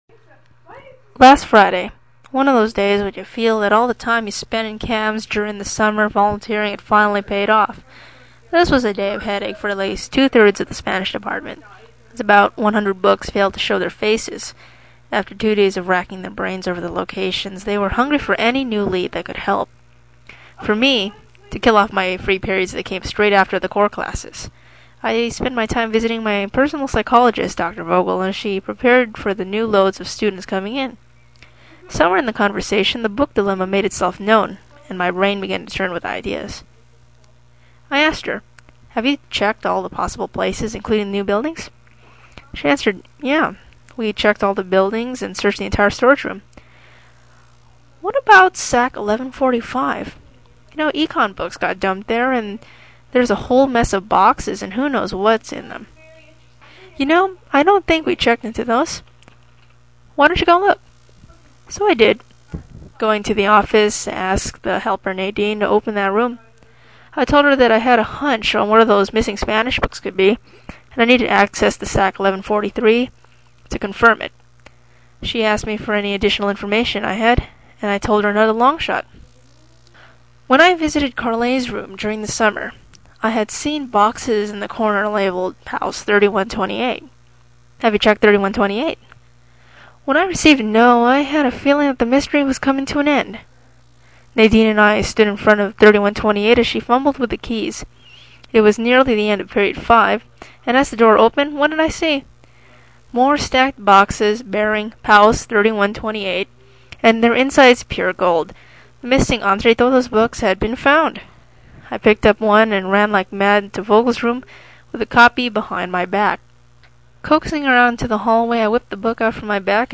Any distortion does not reflect the actual CD track. (It is only a consequence of compression.)